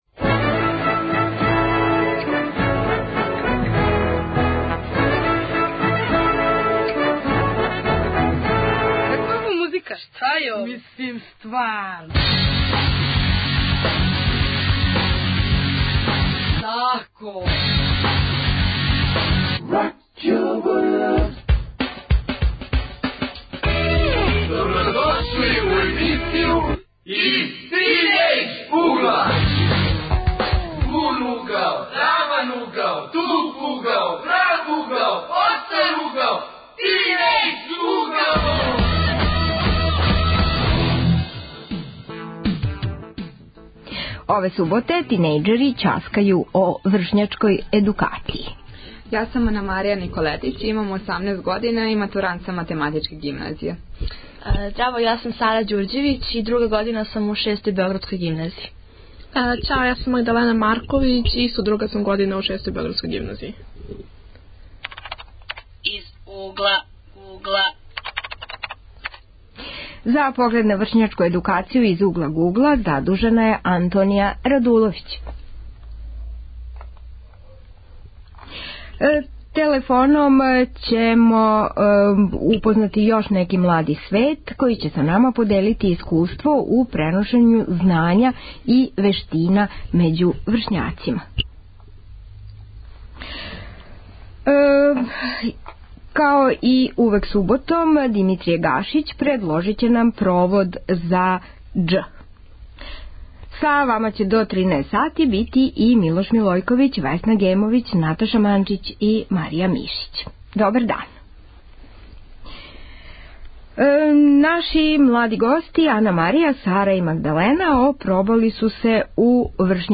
Тема ових тинејџерских разговора јесте вршњачка едукација.